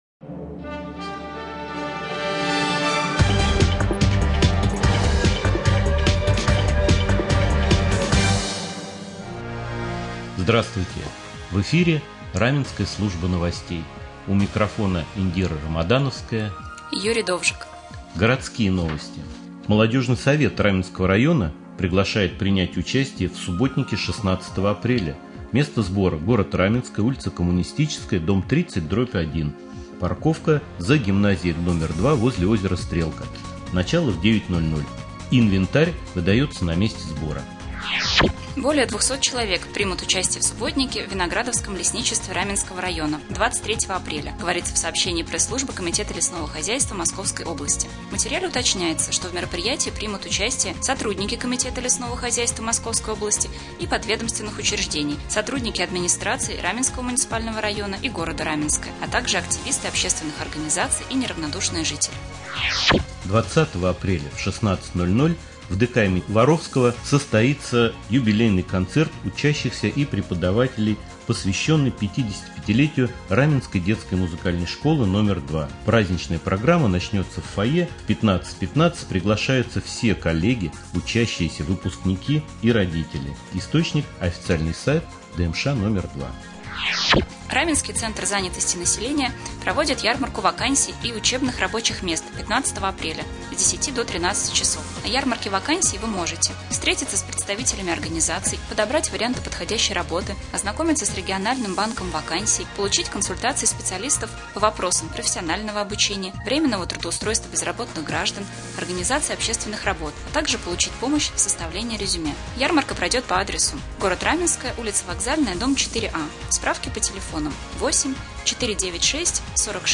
Новости
Прямой эфир.